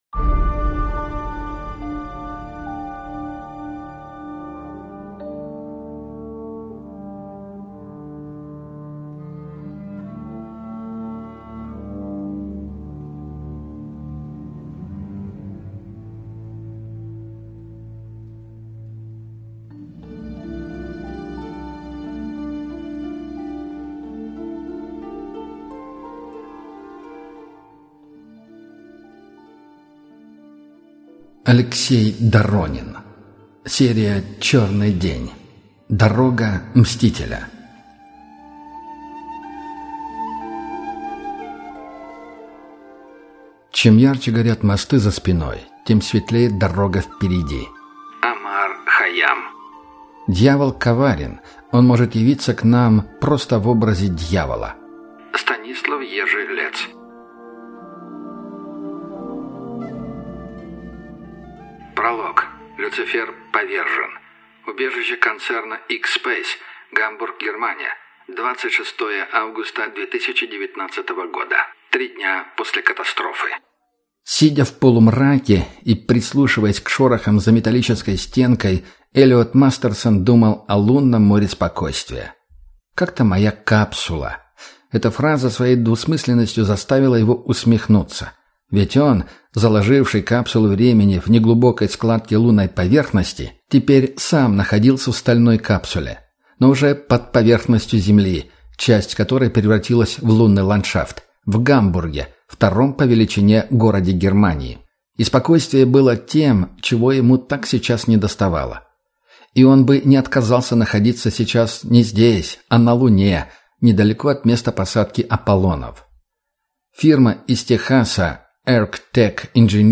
Аудиокнига Дорога мстителя | Библиотека аудиокниг